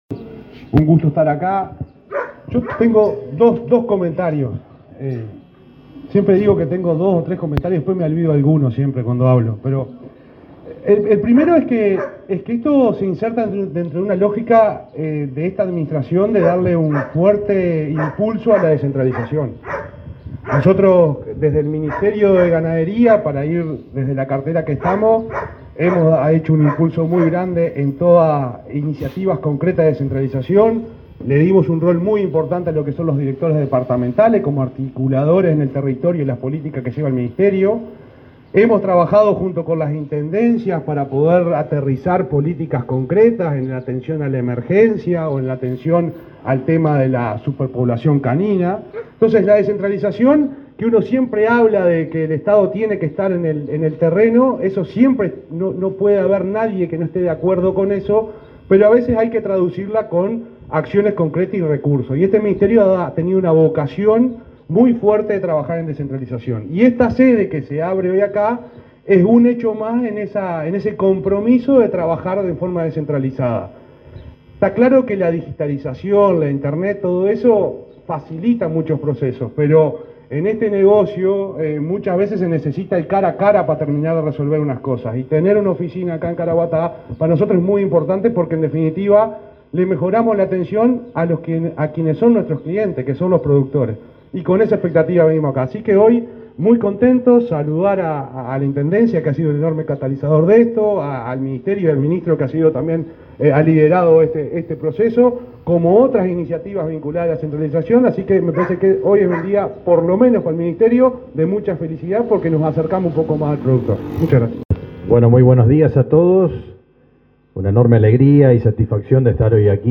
Palabras de autoridades del Ministerio de Ganadería
Palabras de autoridades del Ministerio de Ganadería 22/09/2023 Compartir Facebook Twitter Copiar enlace WhatsApp LinkedIn El subsecretario de Ganadería, Juan Ignacio Buffa, y el ministro Fernando Mattos participaron, en Tacuarembó, en la inauguración de una sede de esa cartera en la localidad de Toscas de Caraguatá.